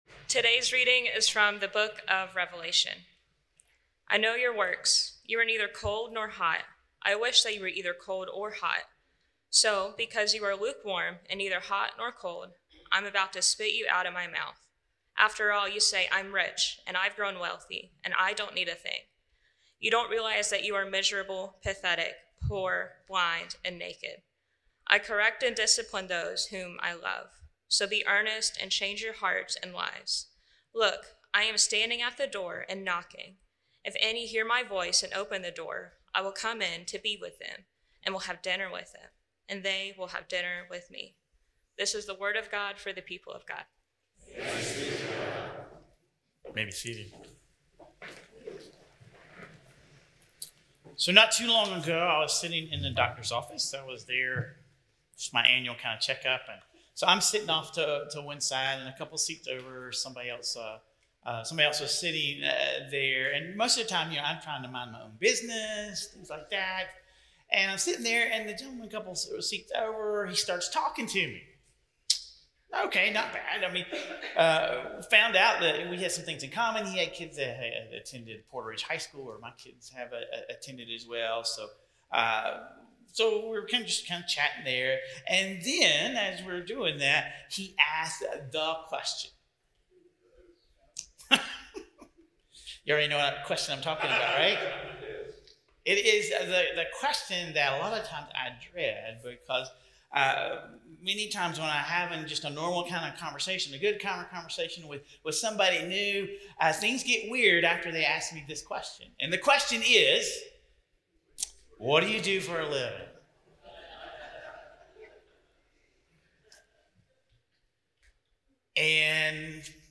Through personal anecdotes and scripture, we learn how to move beyond a lukewarm faith to a life that requires daily acts of faith. Learn how to recognize spiritual complacency and discover a simple, transformative challenge that can revitalize your relationship with God. Sermon Reflections: When have you found yourself being "sort of Christian" — having enough faith to feel good but not enough to make a real difference in your daily life?